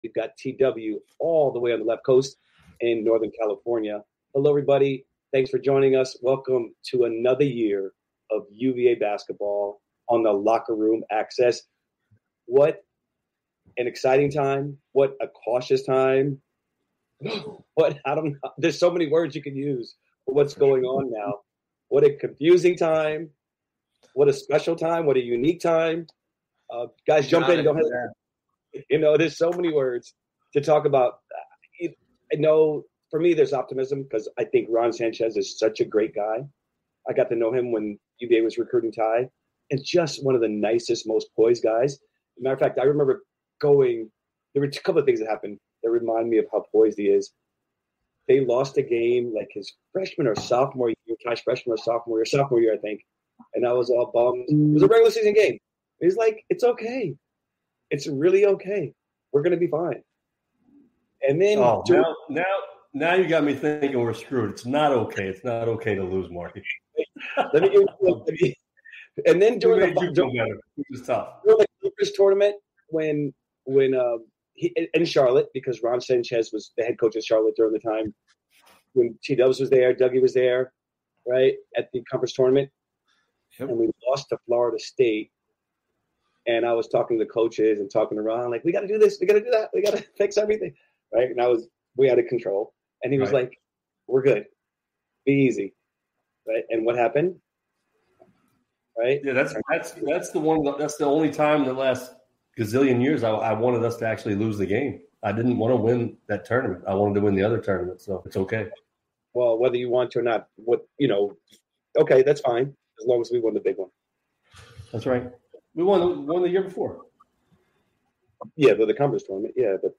The conversation delves into the current state of UVA basketball, reflecting on the recent retirement of Tony Bennett and the transition to Ron Sanchez as head coach. The hosts discuss the implications of NIL and the transfer portal on team dynamics, leadership, and player movements.